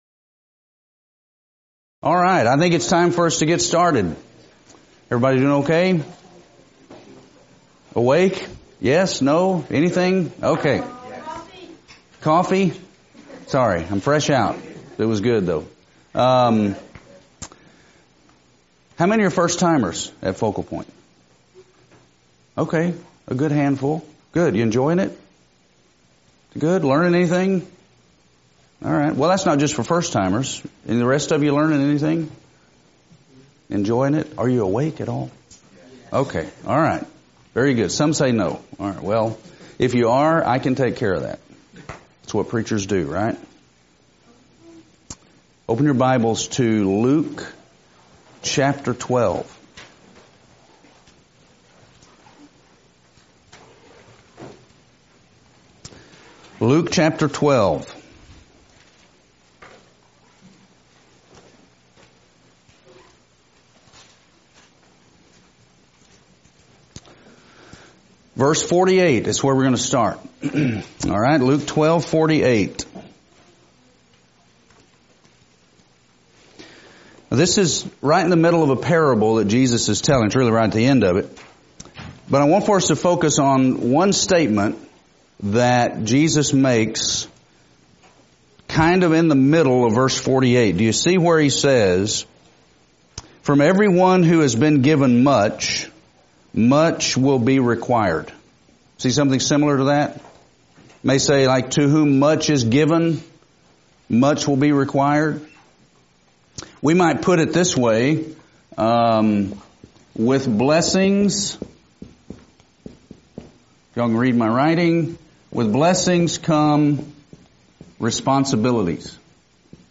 Event: 2018 Focal Point
lecture